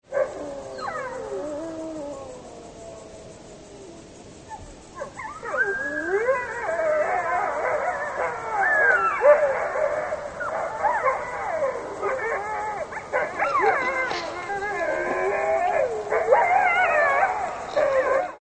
Wycie wilka można z łatwością odróżnić od wycia psa. Pies każdo razowo przed wyciem szczeka oraz kończy szczekaniem, wilki nigdy tego nie robią.
wilk1.mp3